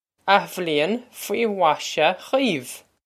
Pronunciation for how to say
Ah-vlee-on fwee vash-ah gweev
This is an approximate phonetic pronunciation of the phrase.